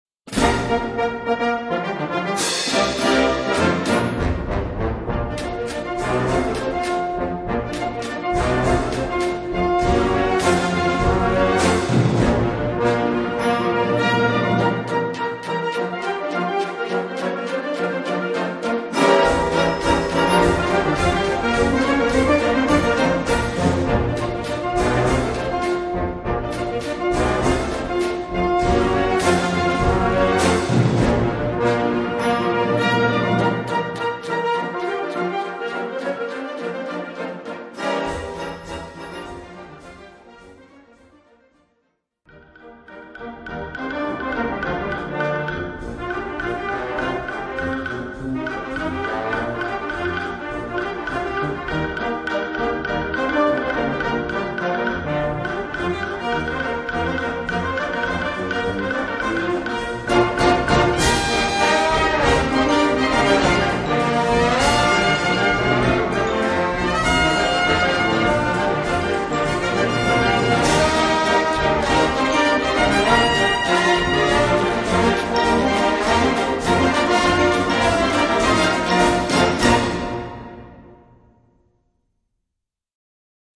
Gattung: Ragtime
Besetzung: Blasorchester